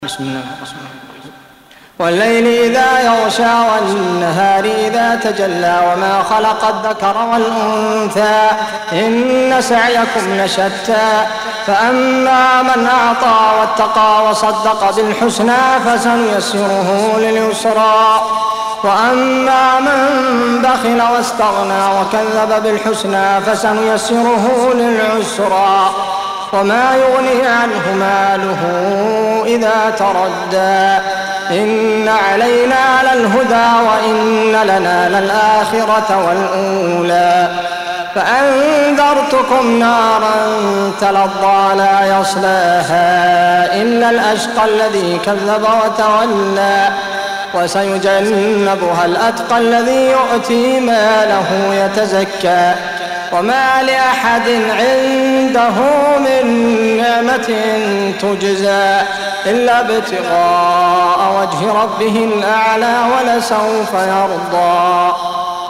92. Surah Al-Lail سورة الليل Audio Quran Tarteel Recitation
Surah Sequence تتابع السورة Download Surah حمّل السورة Reciting Murattalah Audio for 92. Surah Al-Lail سورة الليل N.B *Surah Includes Al-Basmalah Reciters Sequents تتابع التلاوات Reciters Repeats تكرار التلاوات